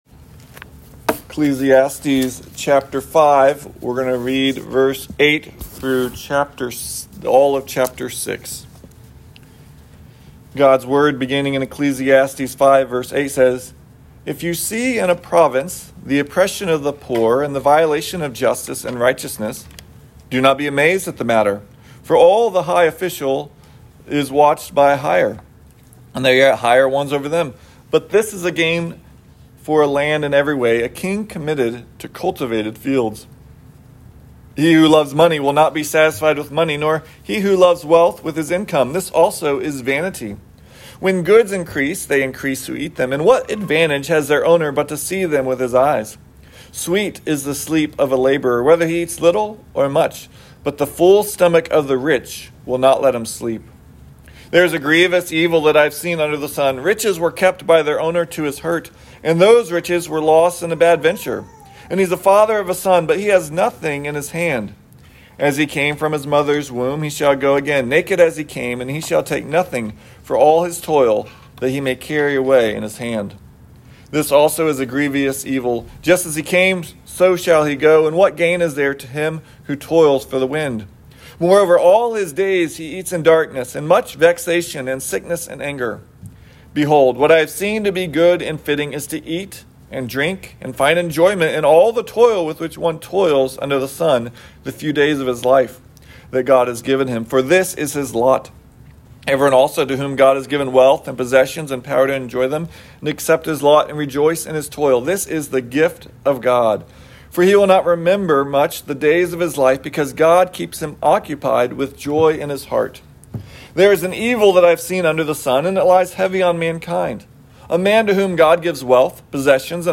2025 Trusting the Giver Preacher